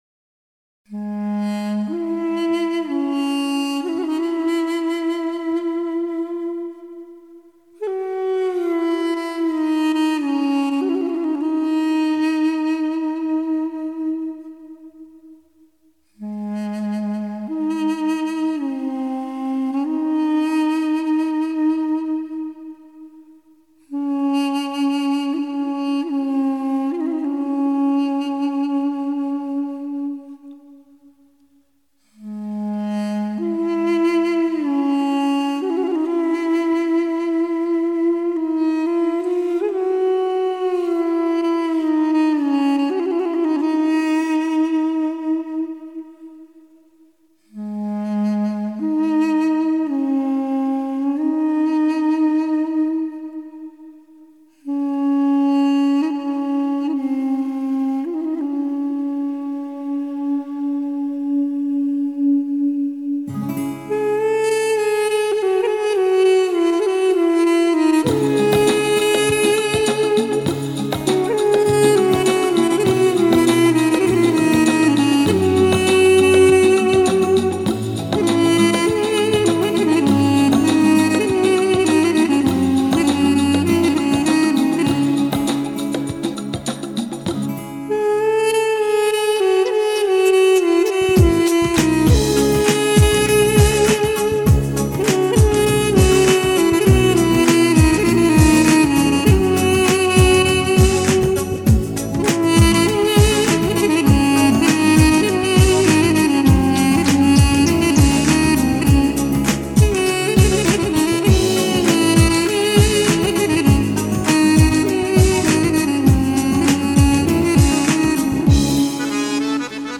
И армянская мелодия
дудук